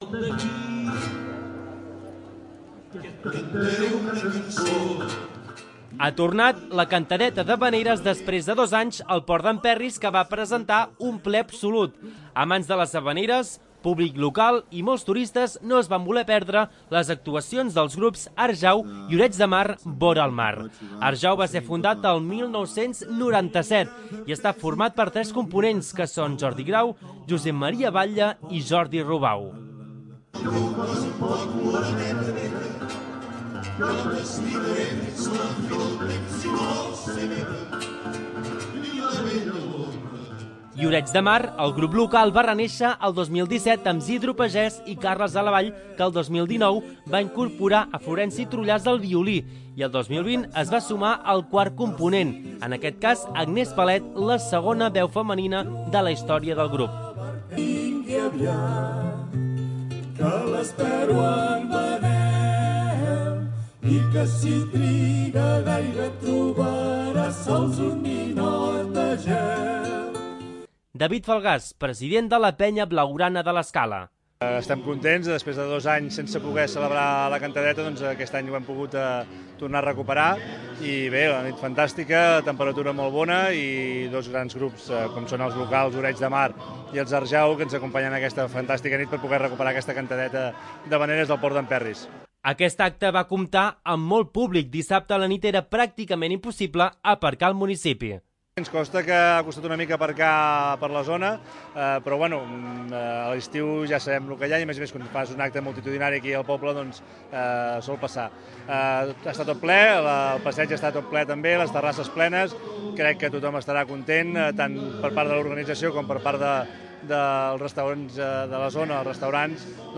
2. L'Informatiu
Ha tornat la 'Cantadeta' d'havaneres després de dos anys al Port d'en Perris, que va presentar un ple absolut.